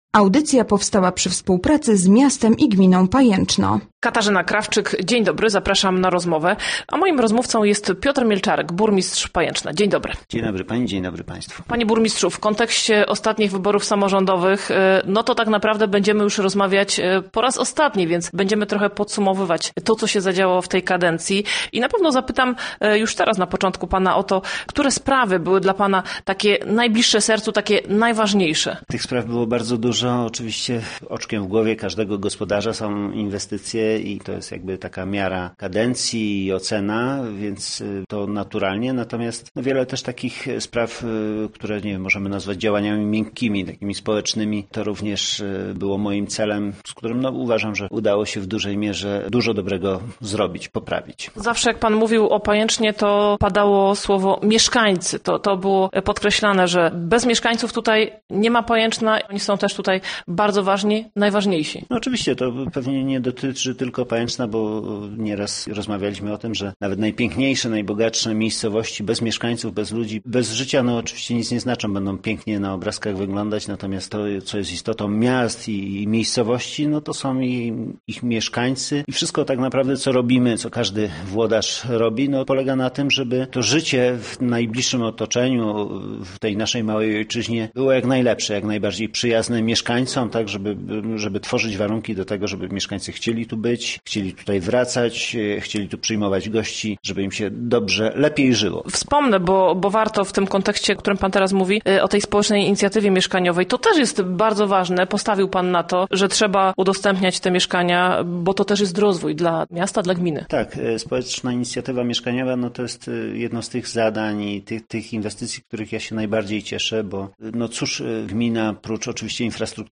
Gościem Radia ZW był burmistrz Pajęczna Piotr Mielczarek